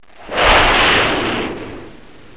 دانلود آهنگ طیاره 50 از افکت صوتی حمل و نقل
دانلود صدای طیاره 50 از ساعد نیوز با لینک مستقیم و کیفیت بالا
جلوه های صوتی